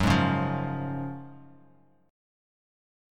GbM7sus2 chord